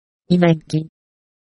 I made the soundfiles with the text-to-speech program at: